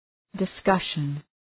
Shkrimi fonetik {dı’skʌʃən}